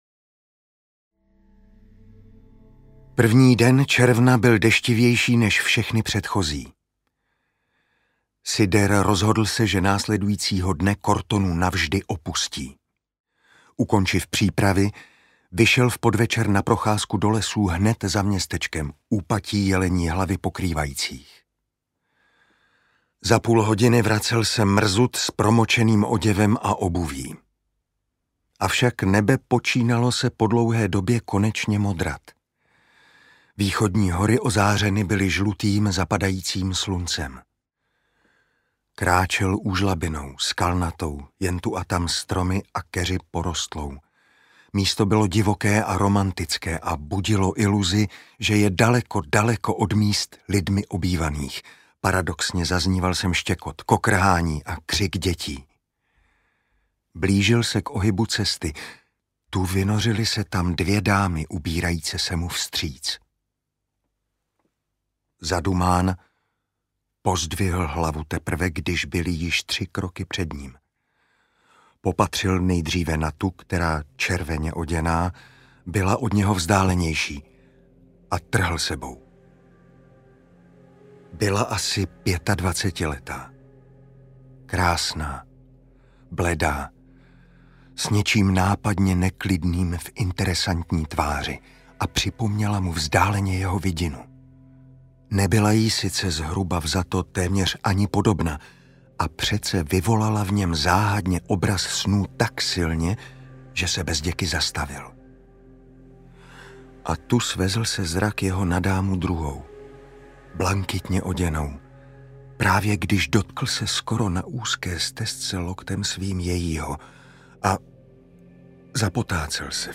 Audiobook
Read: Karel Dobrý